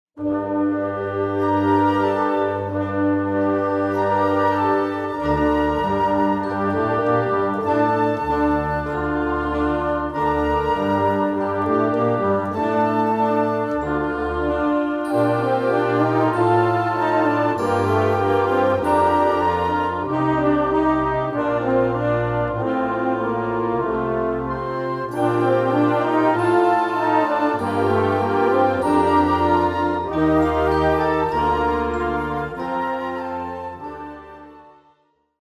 Unterkategorie Suite
Besetzung Ha (Blasorchester)